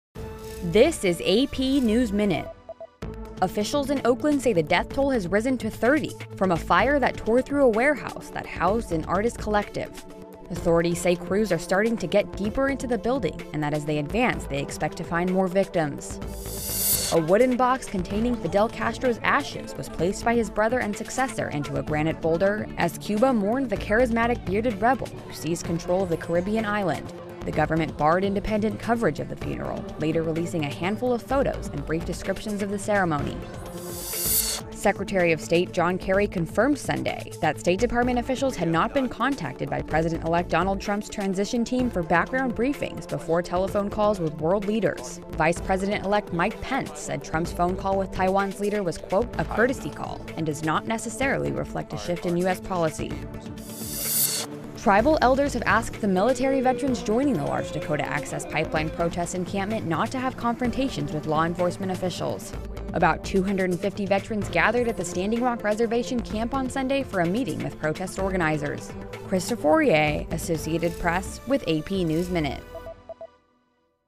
·On-line English TV ·English publication ·broadcasting station ·Classical movie ·Primary English study ·English grammar ·Commercial English ·Pronunciation ·Words ·Profession English ·Crazy English ·New concept English ·Profession English ·Free translation ·VOA News ·BBC World News ·CNN News ·CRI News ·English Songs ·English Movie ·English magazine